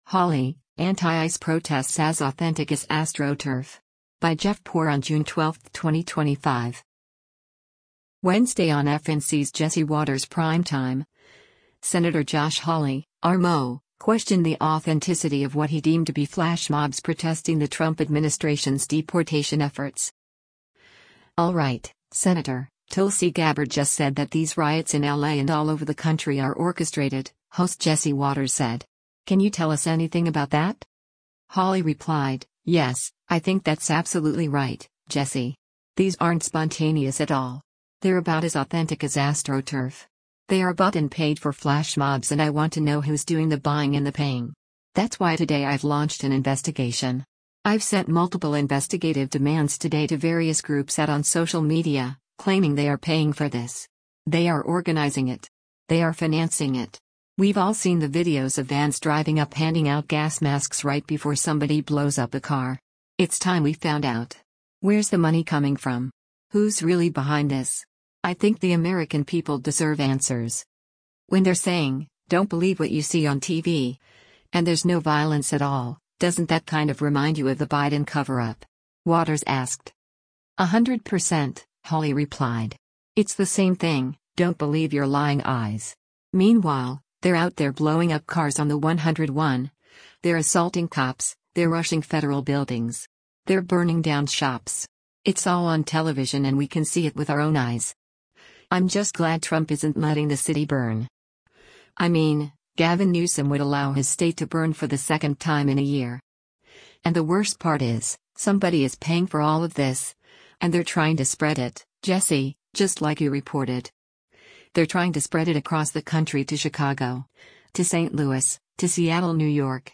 Wednesday on FNC’s “Jesse Watters Primetime,” Sen. Josh Hawley (R-MO) questioned the authenticity of what he deemed to be “flash mobs” protesting the Trump administration’s deportation efforts.